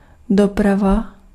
Ääntäminen
IPA: /tʁa.fik/